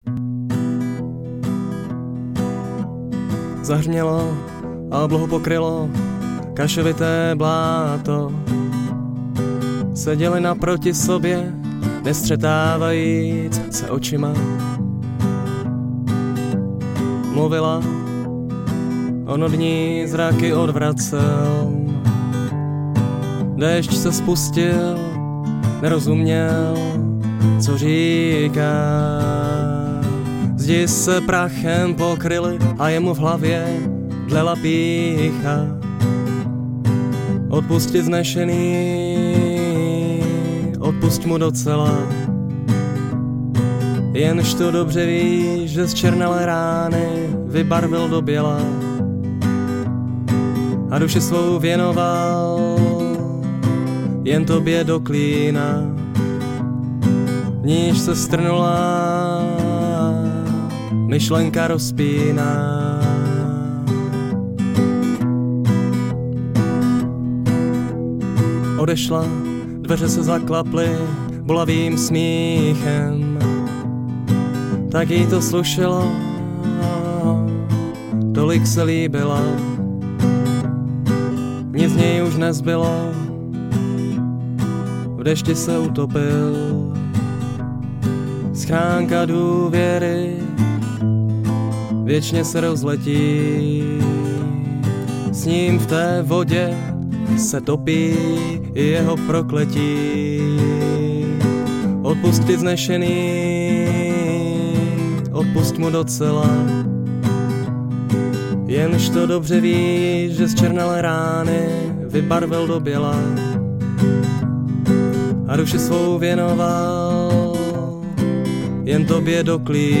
Žánr: World music/Ethno/Folk